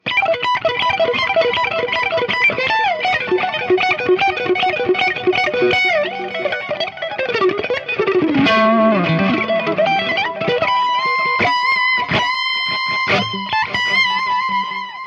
It's incredible sounding when pushed! Bright, edgy and alot of attack!
Lead
RAW AUDIO CLIPS ONLY, NO POST-PROCESSING EFFECTS